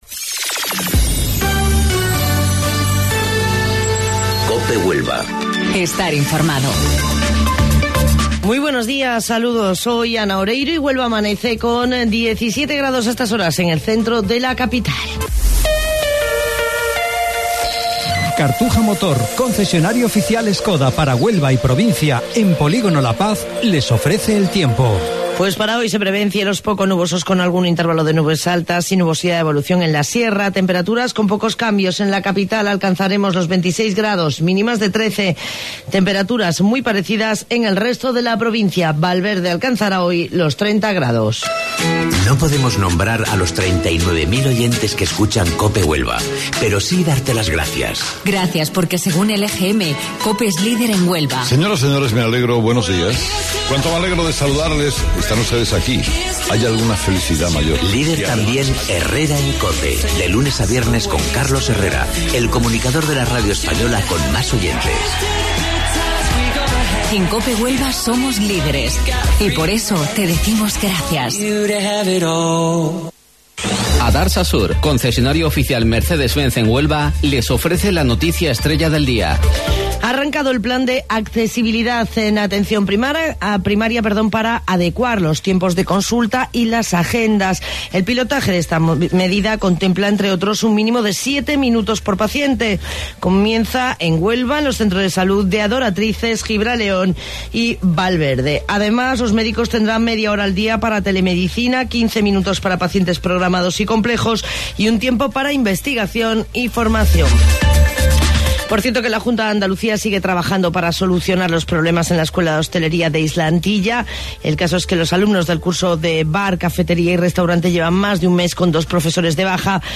AUDIO: Informativo Local 07:55 del 30 de Abril